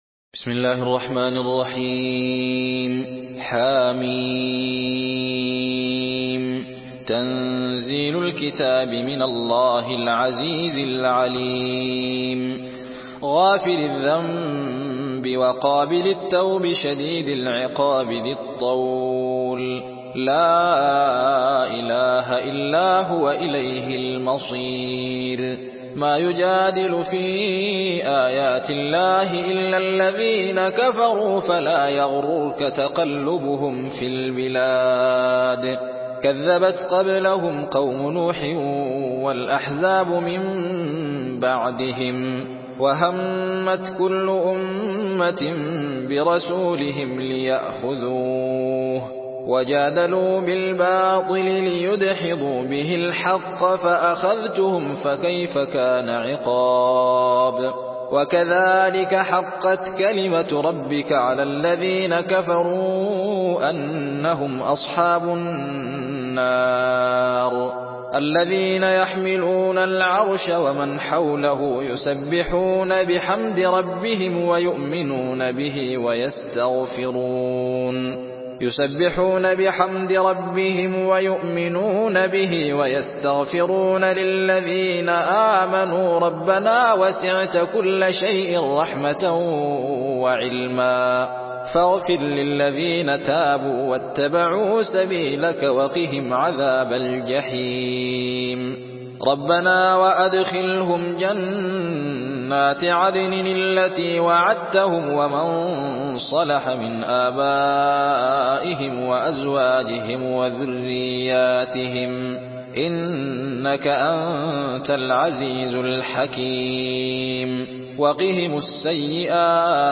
Surah Ghafir MP3 by Alzain Mohamed Ahmed in Hafs An Asim narration.
Murattal